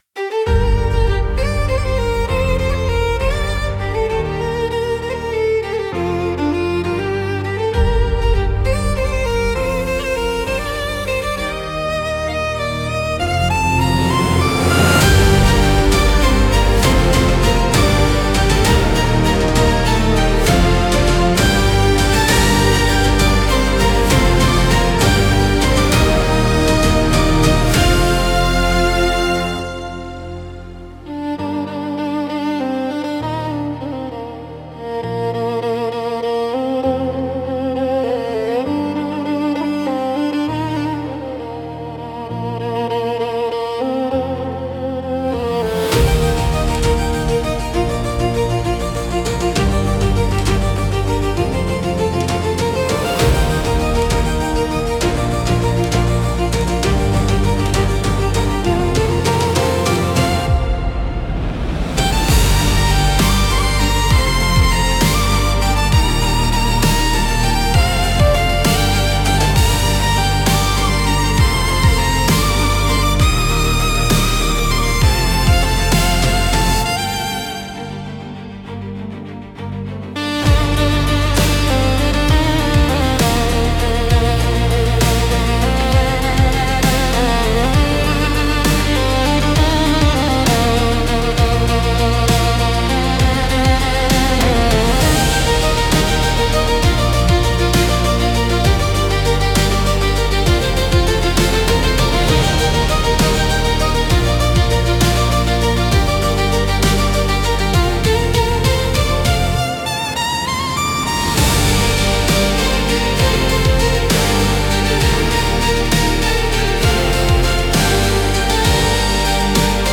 песня ai
Instrumental: